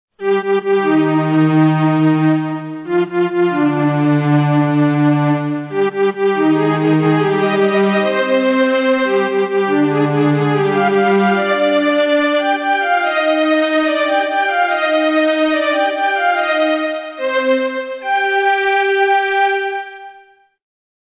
Major third followed by minor third